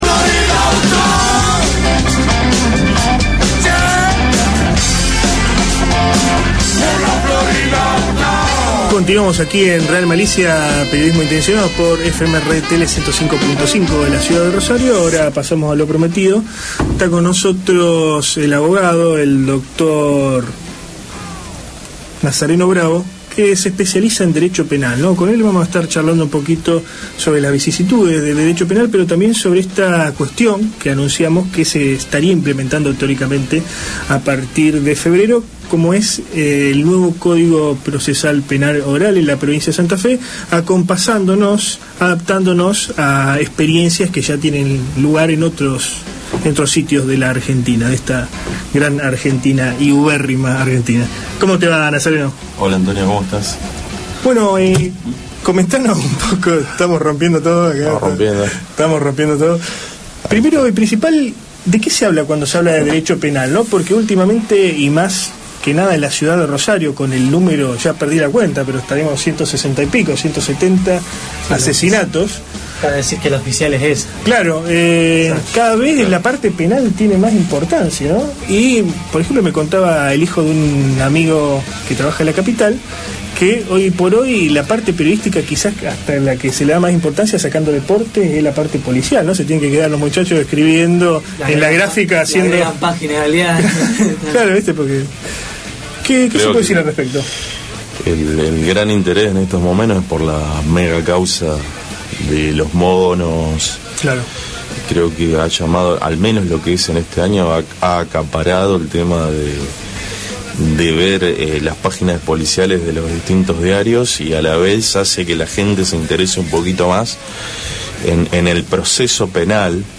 AUDIO ENTREVISTA